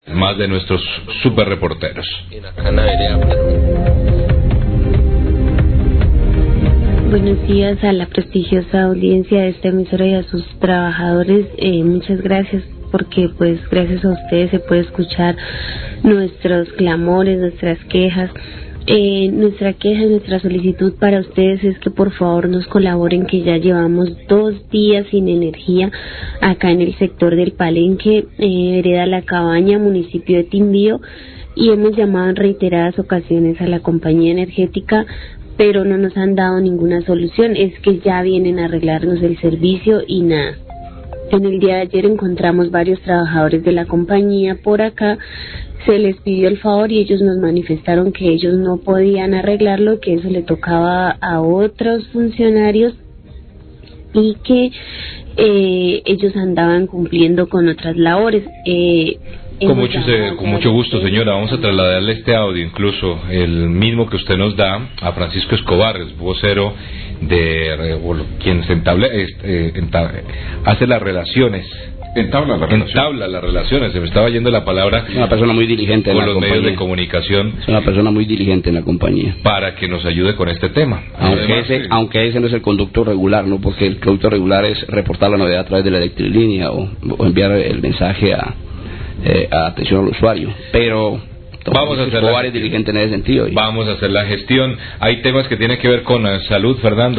Radio
Por linea whatsapp una habitante de la vereda La Cabaña en Timbio, denuncia que llevan dos días de un corte del servicio de energíay al solicitarle a un equipo de operadores de la Compañía atender el daño, éstos les dijeron que no les correspondía realizar la reparación.